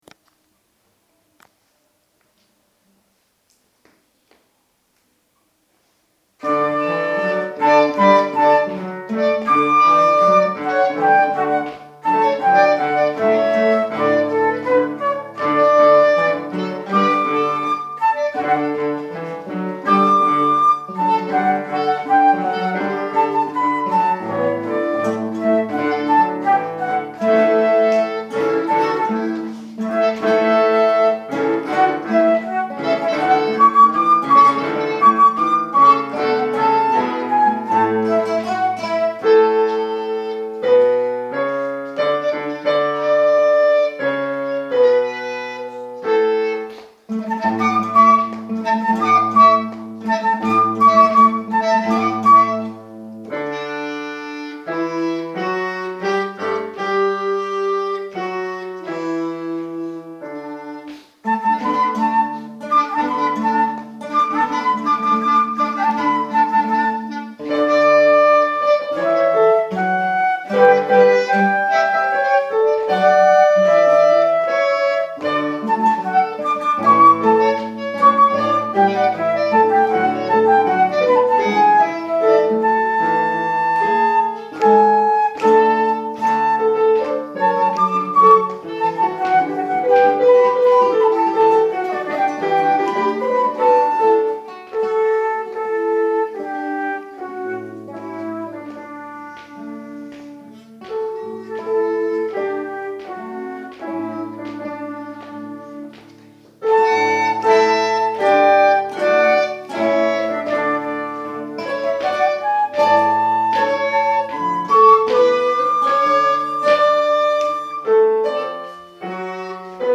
Flauta travesera
Clarinete en Si bemol
Guitarra 1
Guitarra 2
Piano
- Compás: 4/4.
- Tonalidad: Re Mayor
• Interpretaciones en directo:
Toma ensayo 1
Toma_de_ensayo-Aleluya.MP3